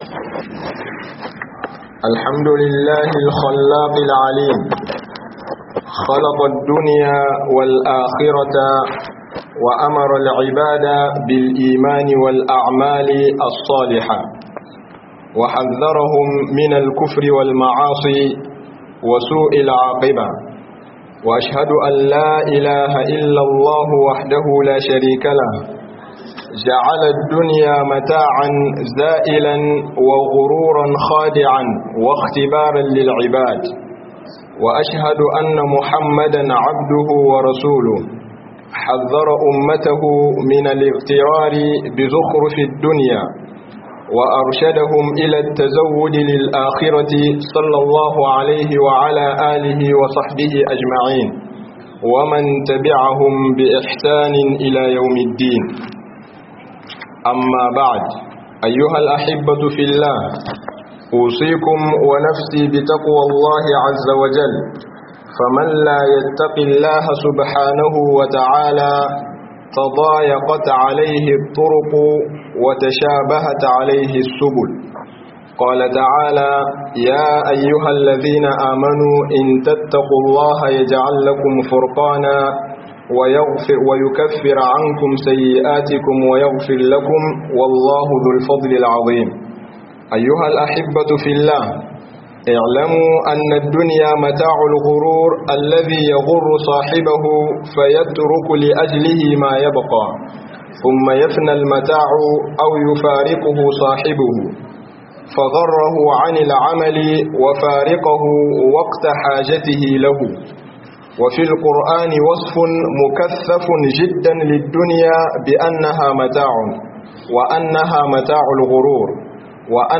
RAYUWAR DUNIYA JIN DADI NE MAI SHUDEWA - Hudubobi